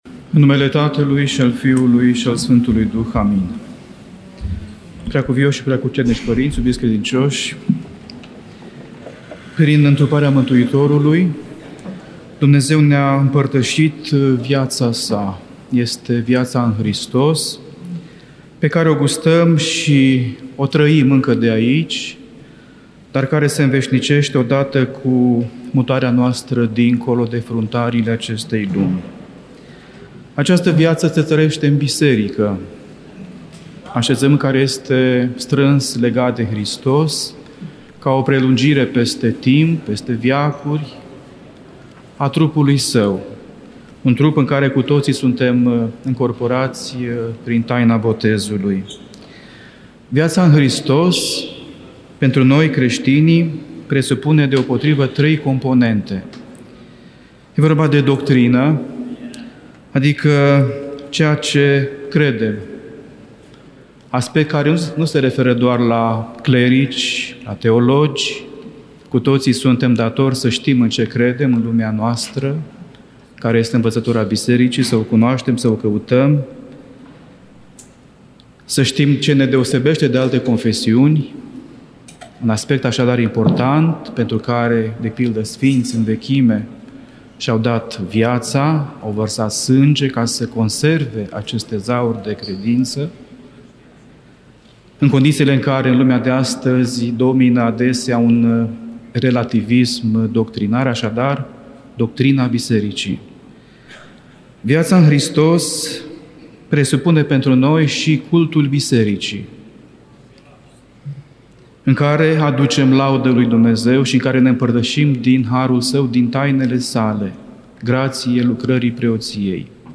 Cuvinte de învățătură Însemnătatea omului pentru Dumnezeu